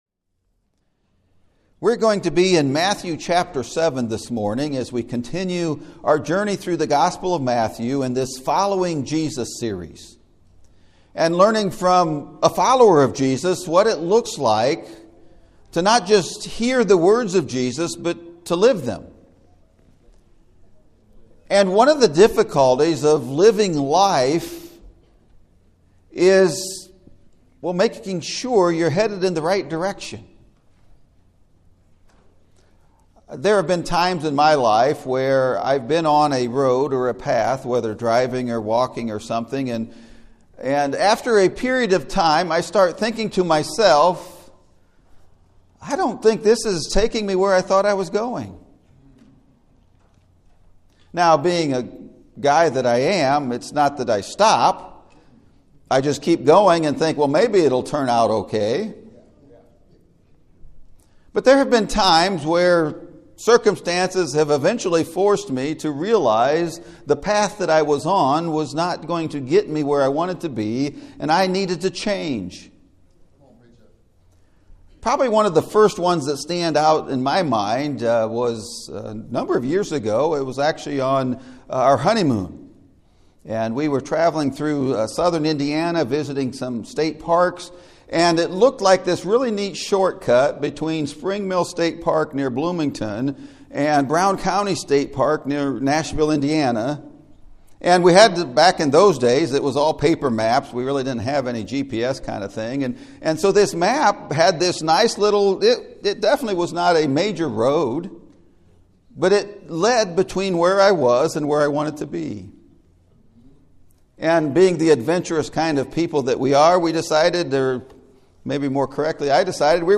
Following Jesus – The Correct PATH! (Sermon Audio)